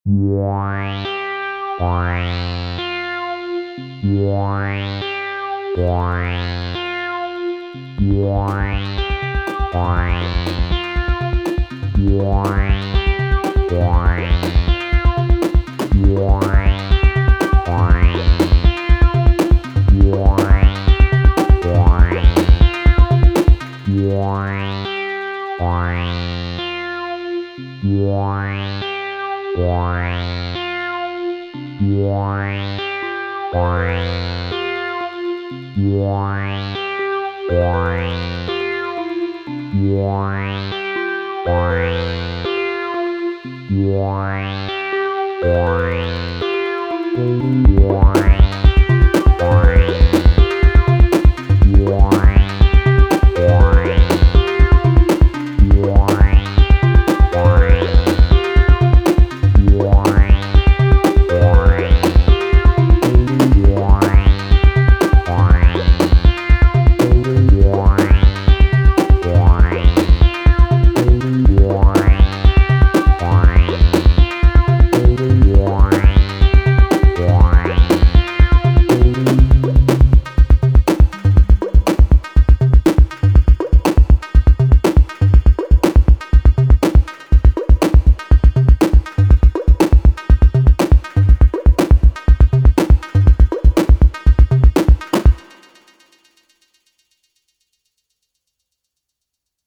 9 = beat/percussion
10 = (acid)bass
11 = synth melody